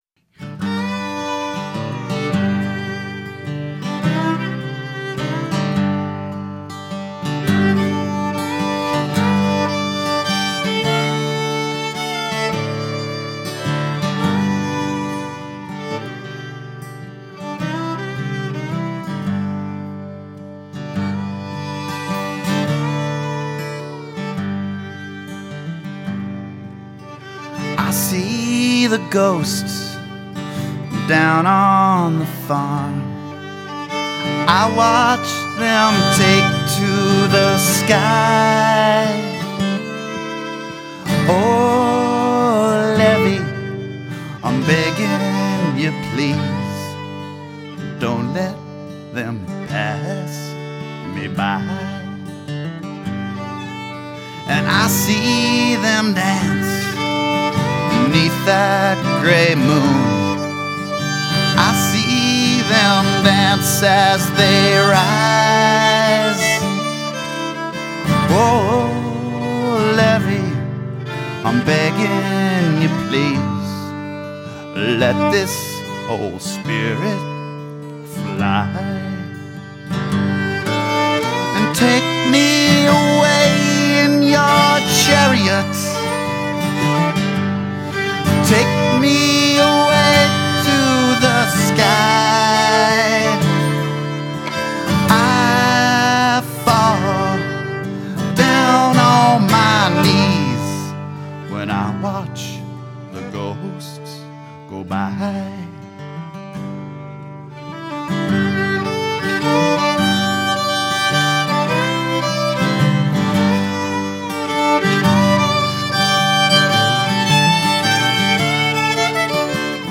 Guitar and vocals
Mandolin and fiddle